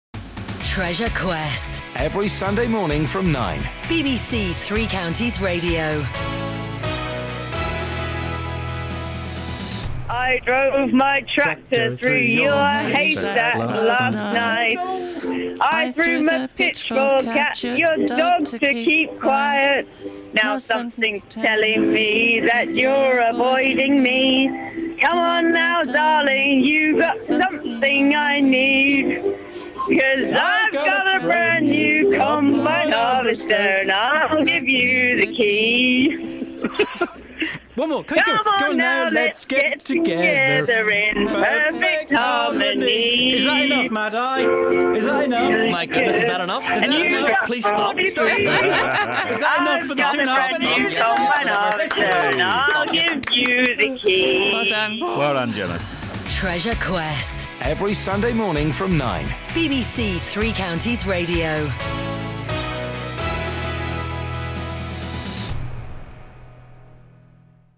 ukelele